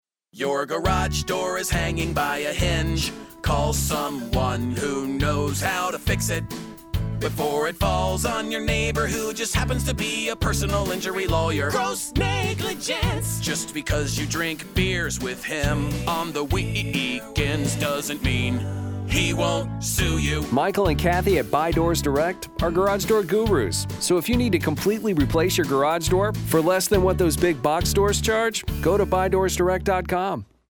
A catchy tune, real humor that stays anchored to the sales message, an engaging presentation of benefits,  clear call-to-action and a single point of contact – well done, Clear Channel!